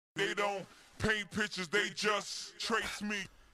Jack_And_The_Beanstalk_Vox.wav